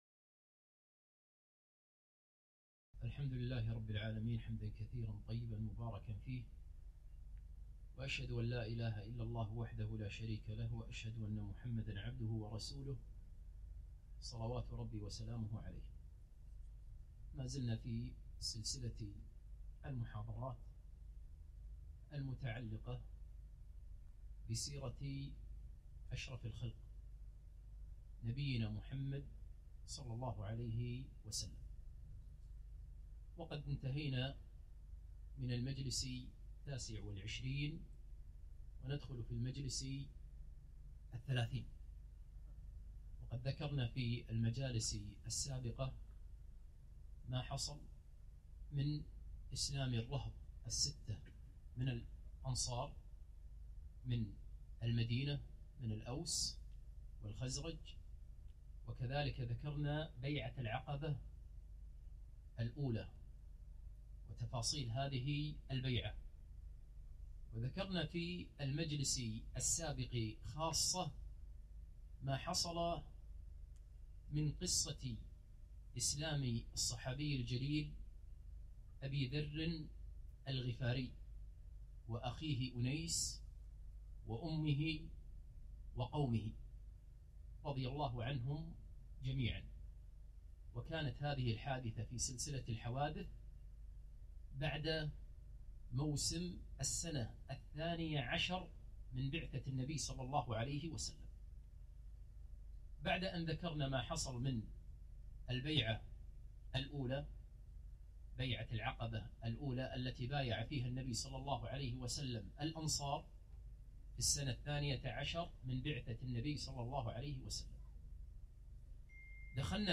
30- تفاصيل وأسرار رحلة الإسراء والمعراج (المحاضرة الأولى)